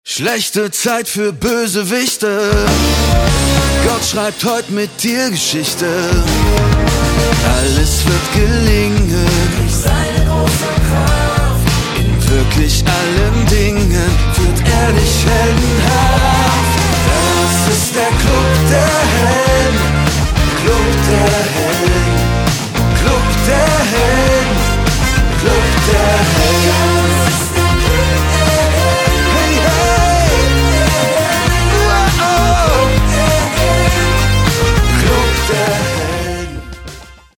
Wie man Riesen besiegt - Folge 1 (MP3-Hörbuch - Download)
• Sachgebiet: Hörspiele